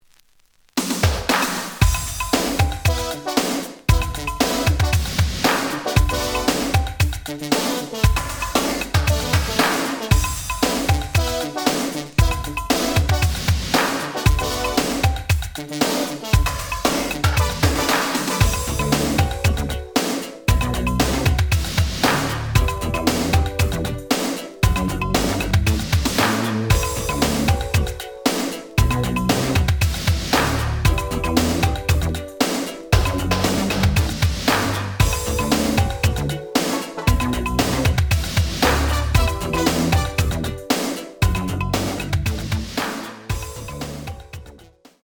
試聴は実際のレコードから録音しています。
●Genre: Hip Hop / R&B